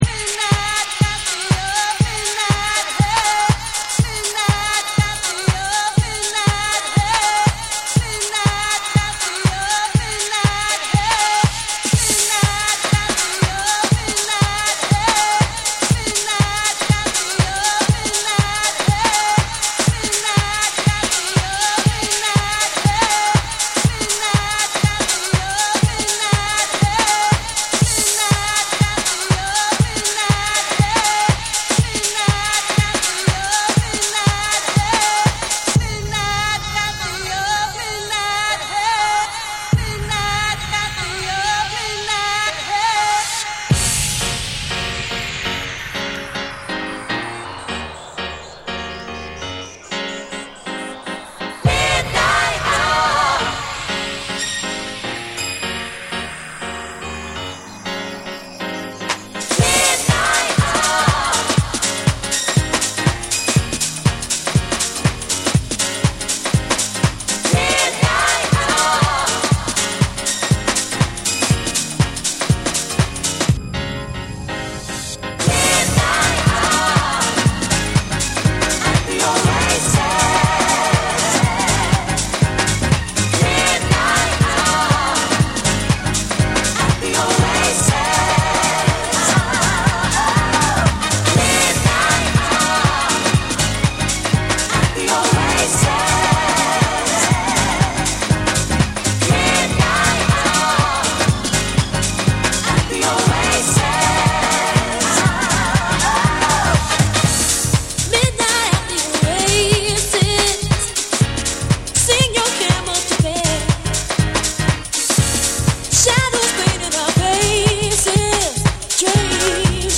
オリジナルのメロウな魅力を軸に、ハウス〜ブレイクビーツ感覚まで行き交う、フロア対応力の高い一枚。
TECHNO & HOUSE / SOUL & FUNK & JAZZ & etc